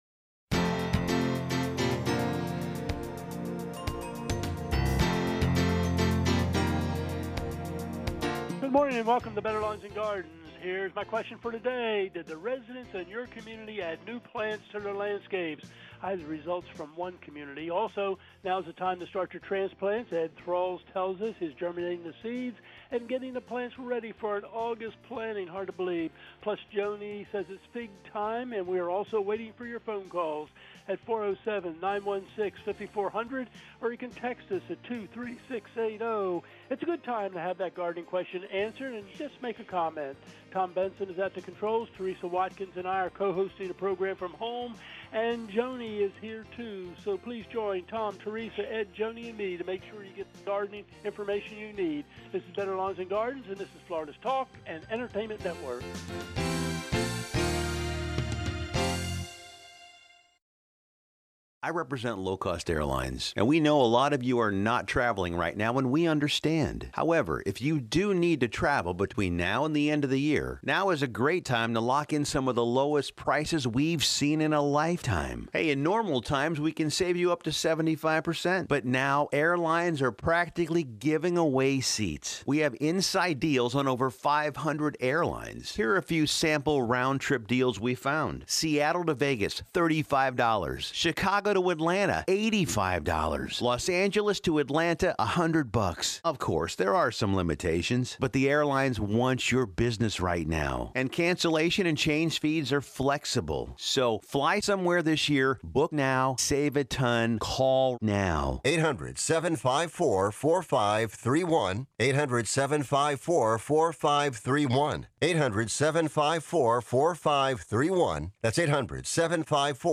gardening show